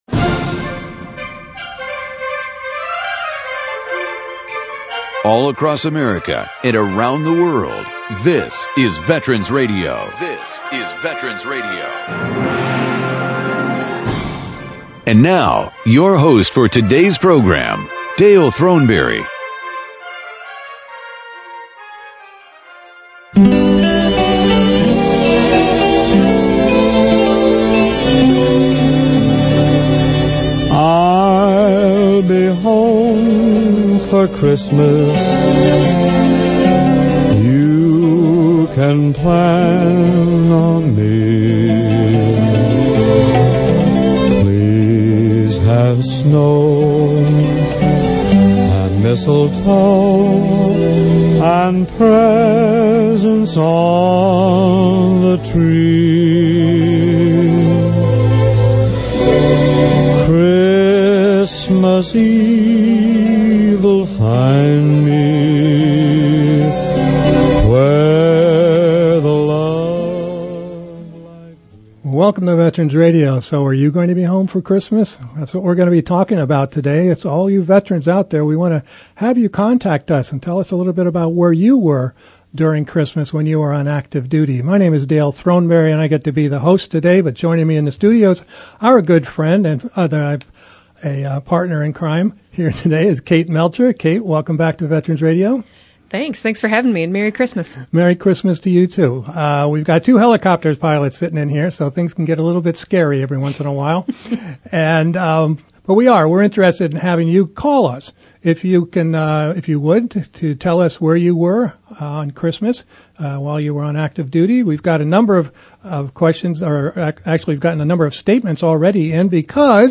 They will be playing some Bob Hope clips from his USO shows.